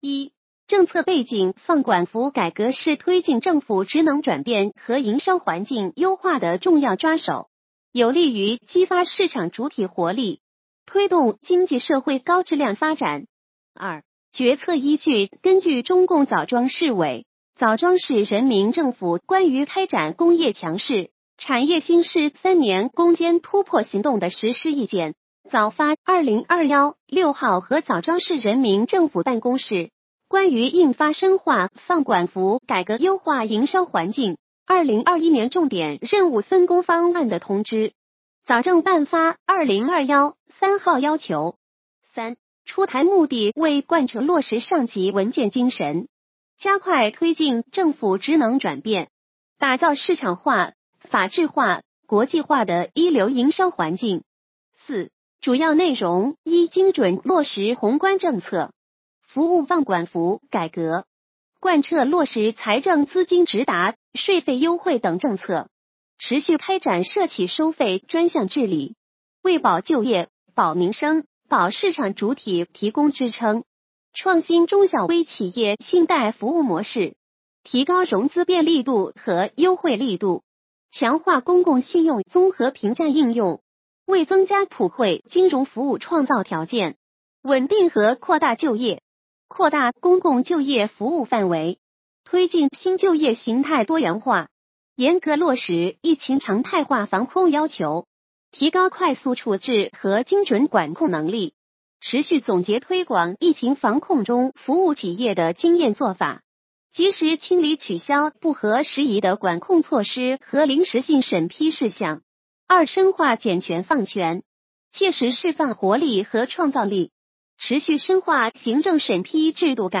语音解读：山亭区人民政府办公室关于印发深化“放管服”改革优化营商环境2021年重点任务分工方案的通知